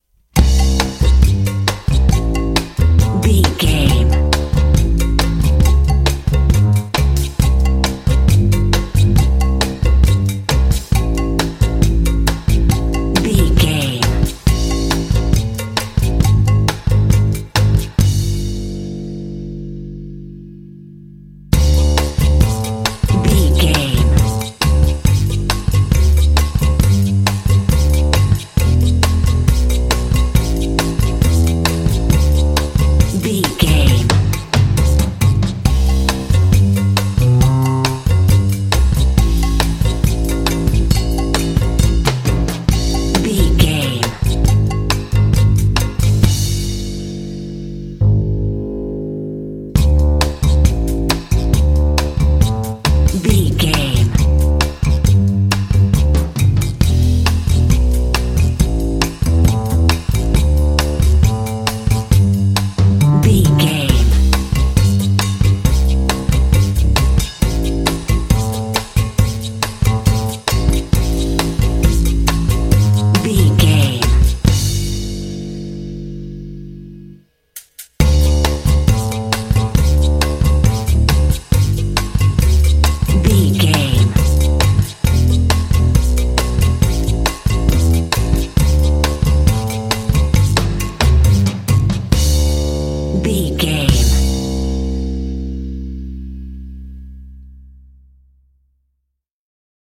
Ionian/Major
playful
uplifting
calm
cheerful/happy
bass guitar
percussion
underscore